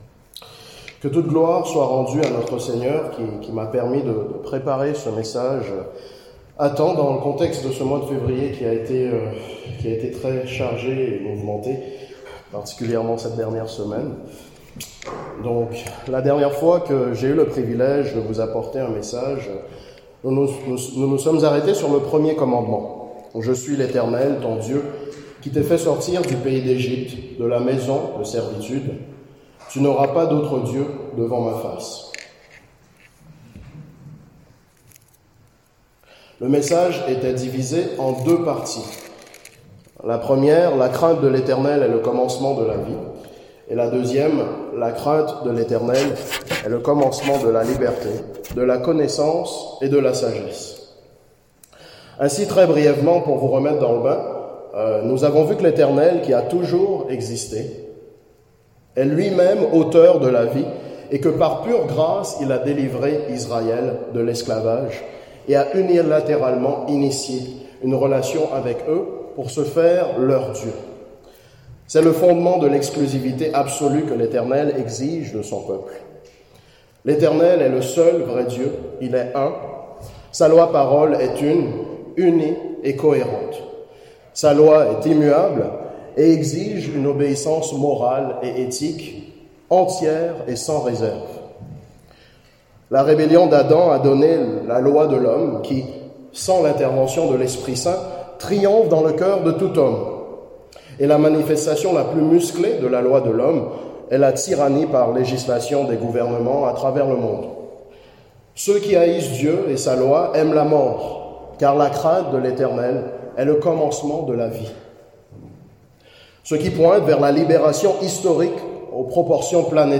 Serie de sermons